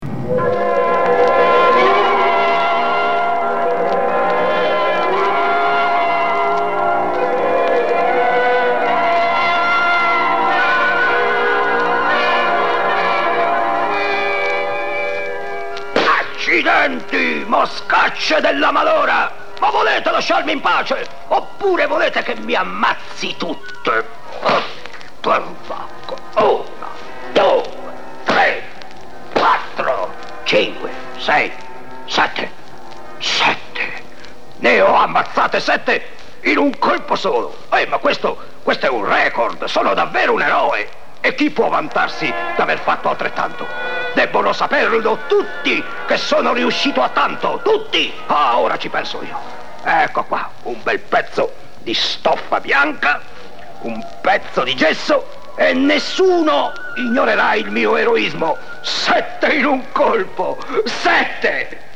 R.: ecco qua il nostro album sperimentale.